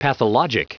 Prononciation du mot pathologic en anglais (fichier audio)
Prononciation du mot : pathologic